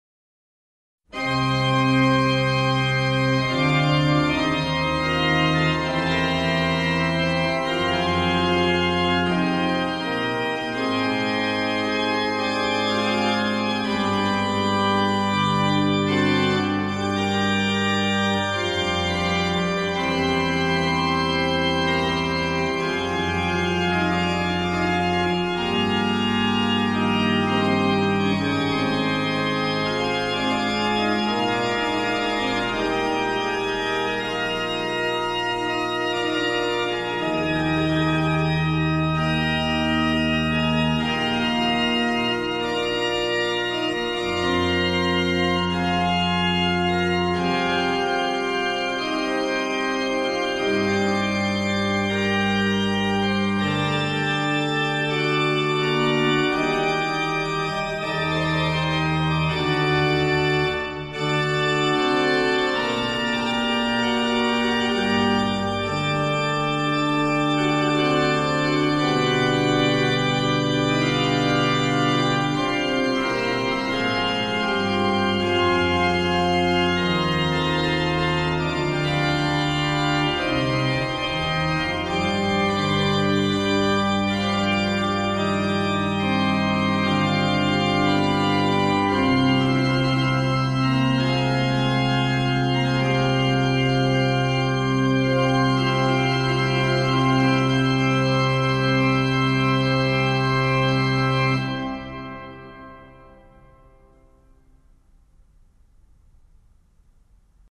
Extrait musical
Champagnole – Orgues Marin Carouge 1721 (Classé M.H.)